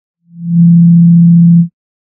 Vibration.mp3